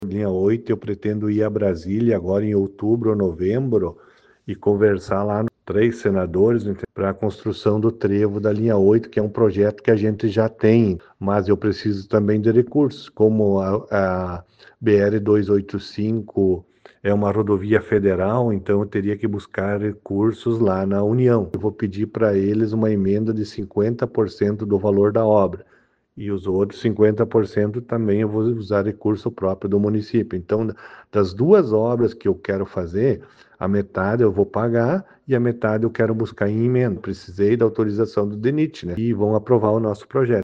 (Abaixo, sonora de Bráulio Scherer)